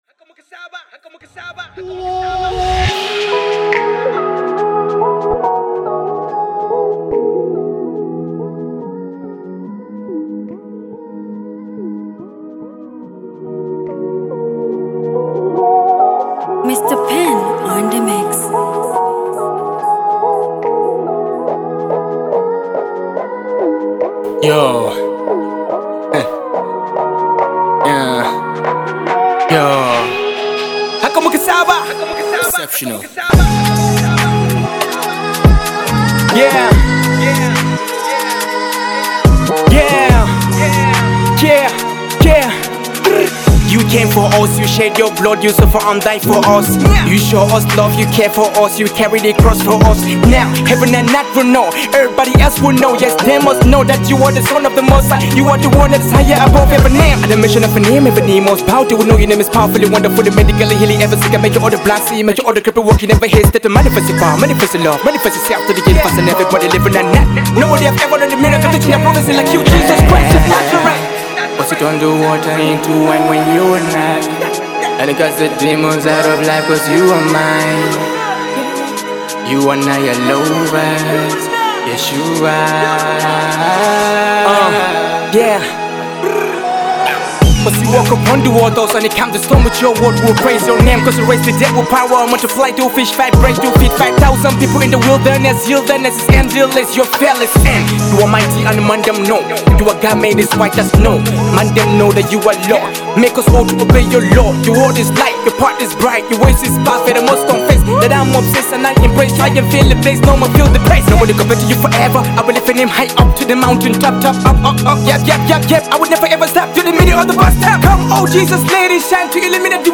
Naija Gospel Songs